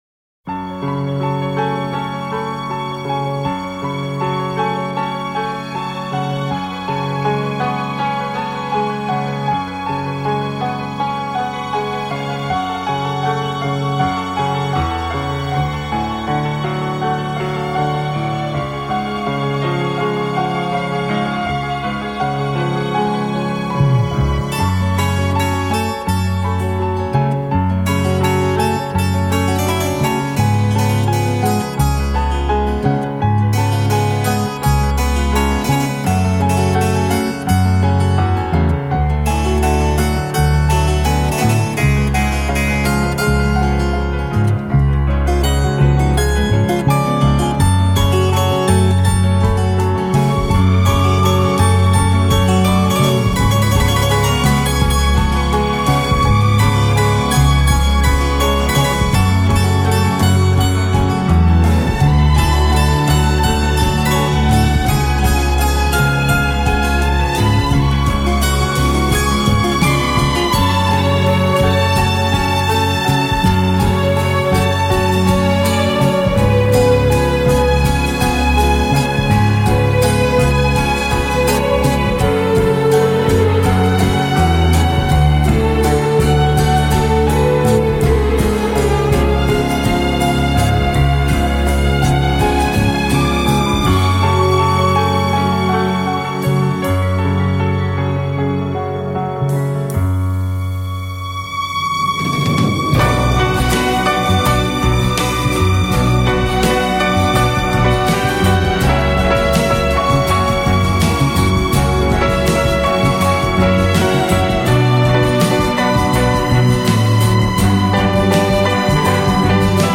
浪漫弦乐和流行风格的完美融合 精装6CD超值价答谢乐迷